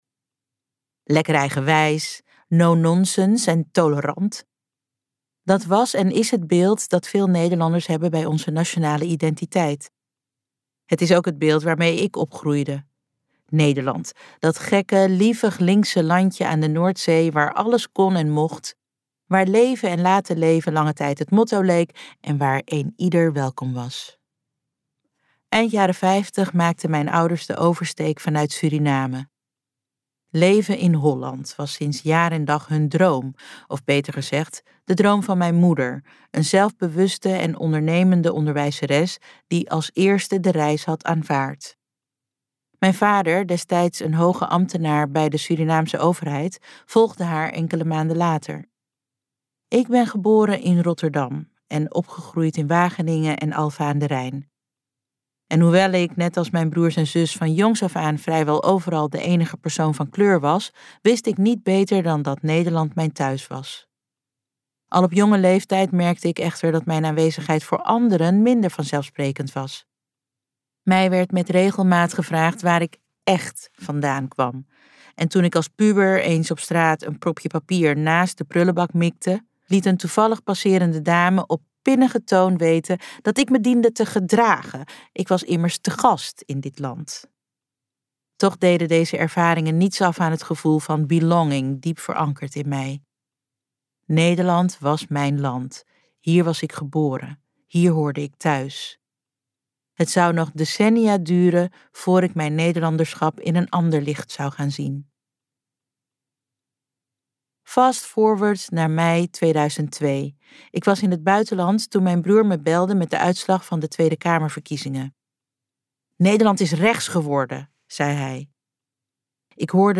Ambo|Anthos uitgevers - Kan ik nog thuis zijn in dit land luisterboek